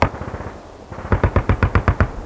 city_battle5.wav